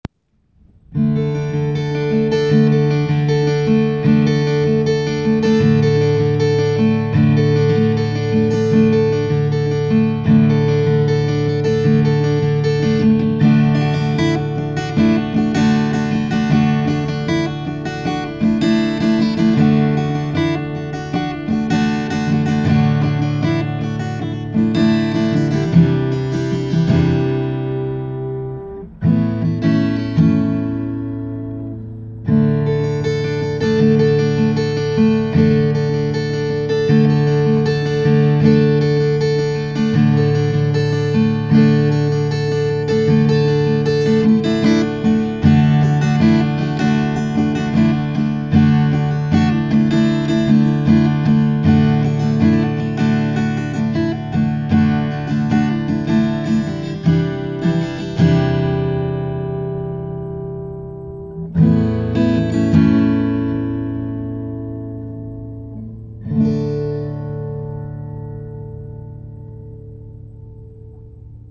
Below is a recording I made while on the rocks looking off into the endless lake of water.
Midnight-Superior-Shore.wav